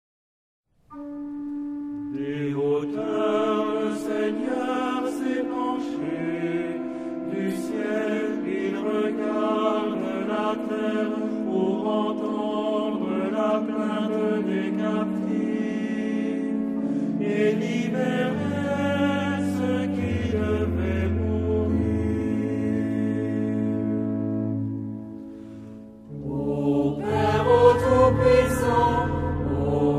Tonart(en): a-moll